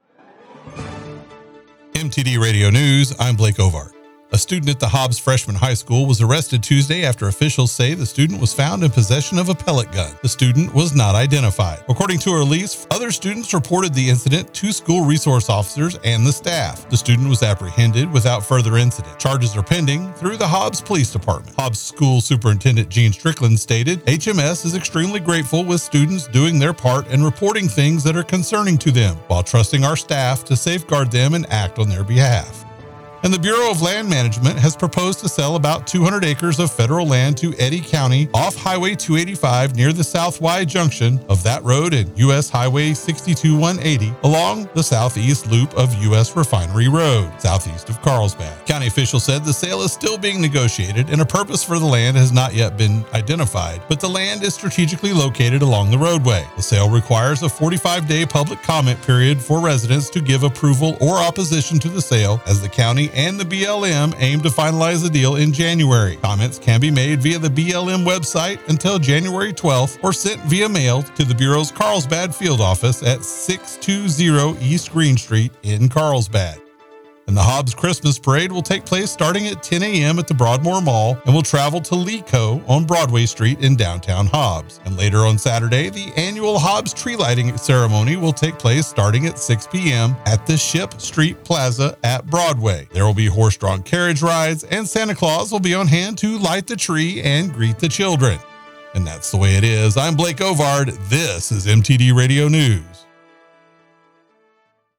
W105 NEWS NEW MEXICO AND WEST TEXAS